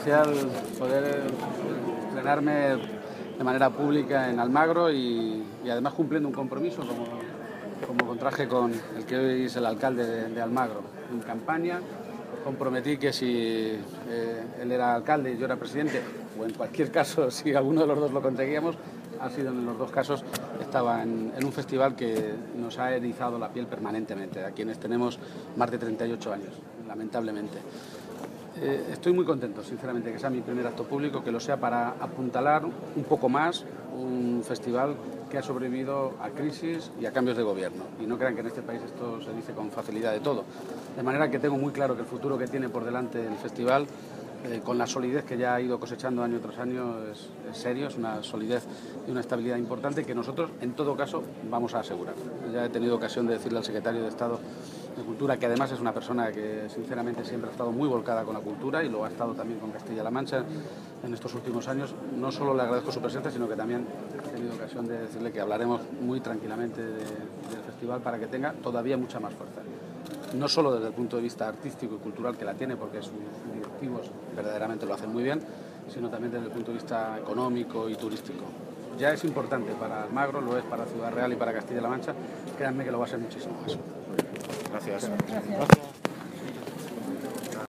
El Presidente electo de Castilla-La Mancha, Emiliano García-Page, ha protagonizado hoy su primer acto público como jefe del Ejecutivo regional en la inauguración del Festival Internacional de Teatro Clásico de Almagro.